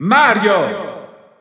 Italian Announcer announcing Mario.
Mario_Italian_Announcer_SSBU.wav